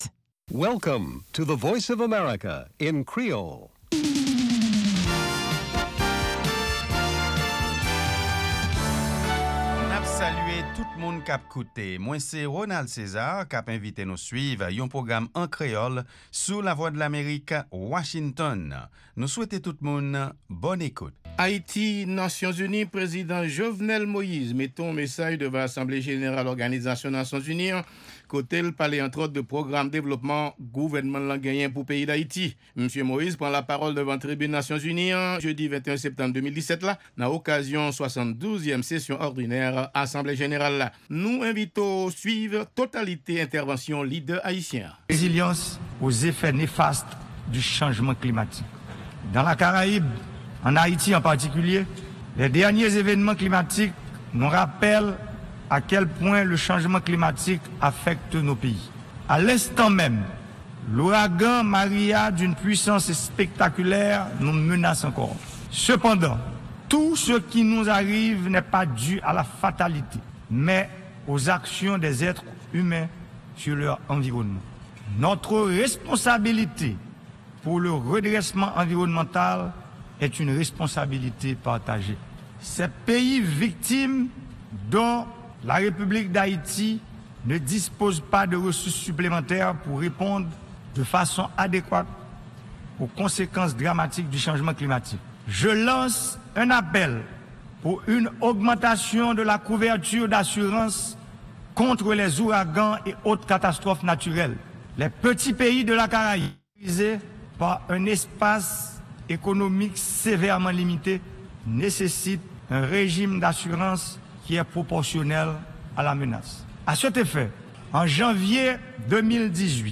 Ayiti-ONU: Entèvansyon Prezidan Jovenel Moise nan 72èm Asanble Jeneral Nasyon Zini an nan New York.